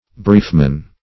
Search Result for " briefman" : The Collaborative International Dictionary of English v.0.48: Briefman \Brief"man\ (br[=e]f"man), n. 1.